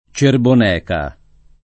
cerboneca [ © erbon $ ka ]